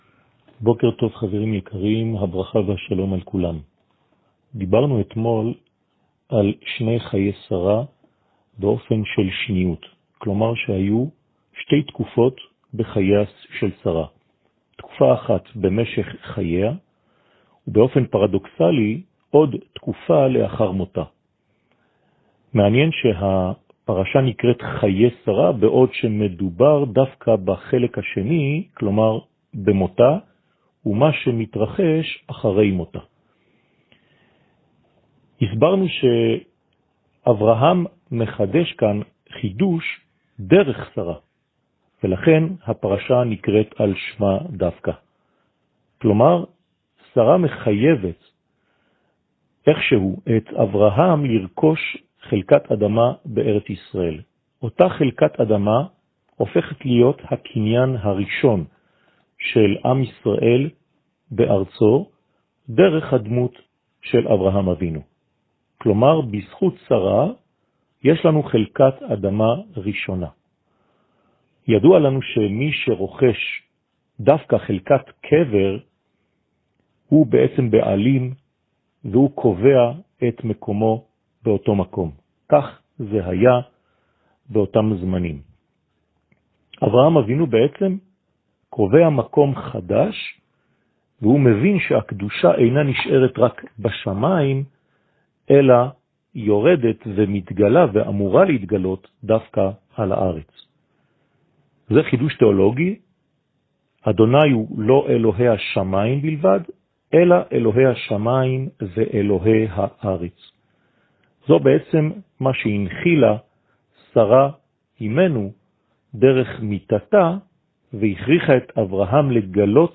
שיעור מ 09 נובמבר 2020